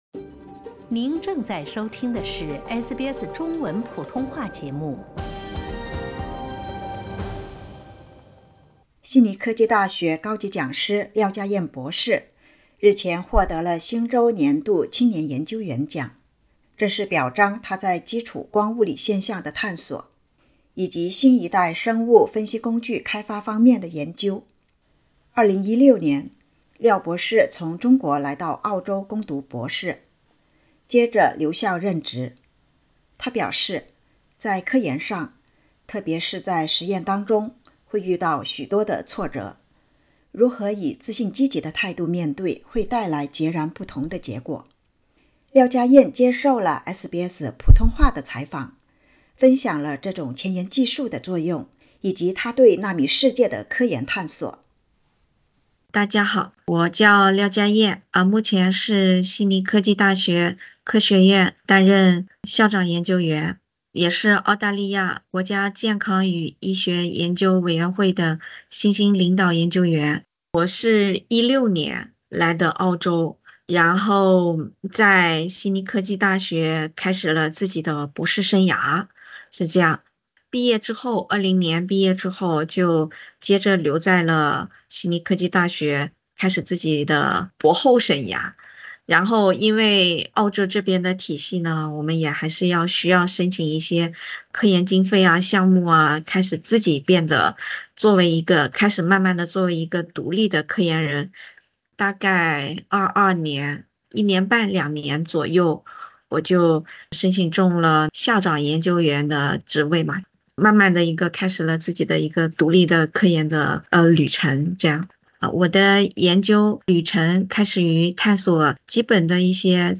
接受SBS普通话采访时，她谈起对纳米世界的科研探索。